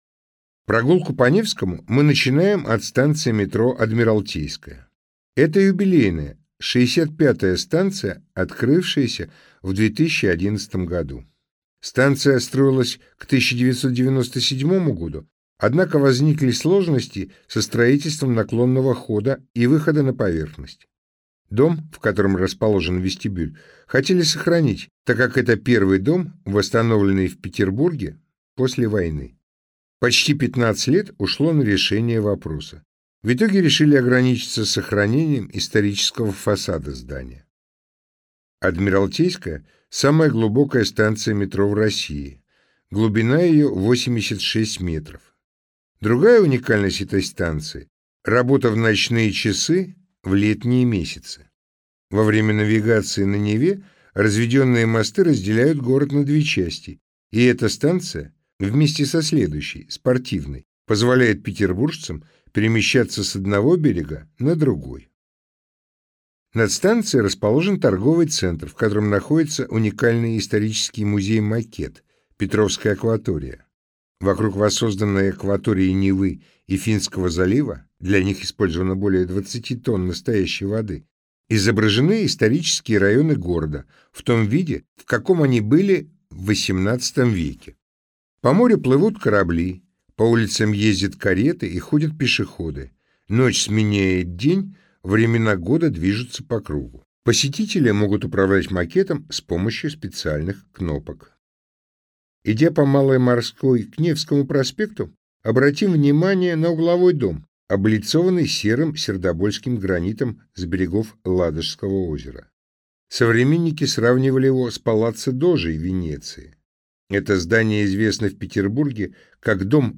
Аудиокнига Петербург: пешком по городу | Библиотека аудиокниг